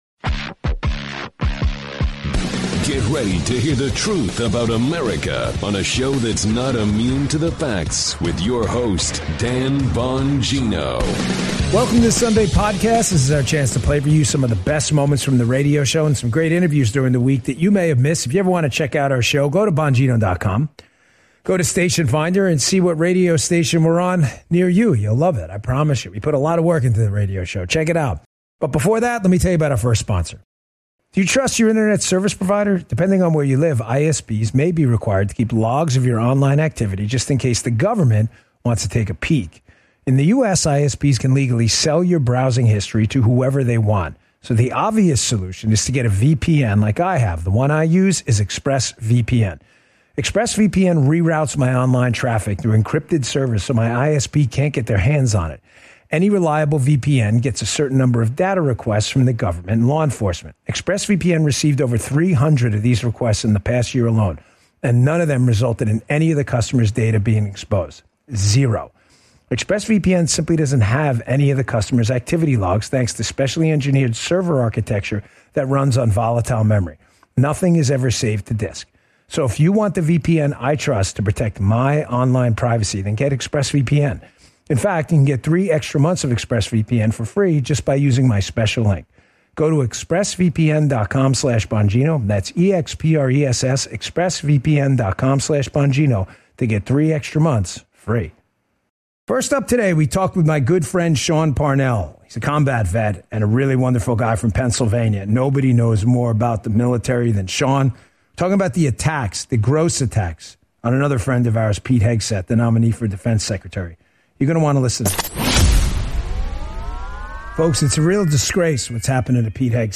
He also chats with combat veteran Sean Parnell about the challenges veterans face, especially in the media. They discuss how veterans, despite their struggles, are great leaders because of their war experiences.
The Dan Bongino podcast hosted by Dan Bongino features highlights from his radio show and interviews.